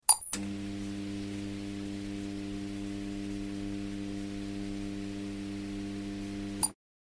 Neon Light Buzzing Sound Effect
Hear the classic hum and electric buzz of a flickering neon light. This sound effect delivers the signature noise of neon signage, enhancing retro scenes, sci-fi environments, urban nightscapes, and eerie atmospheres.
Neon-light-buzzing-sound-effect.mp3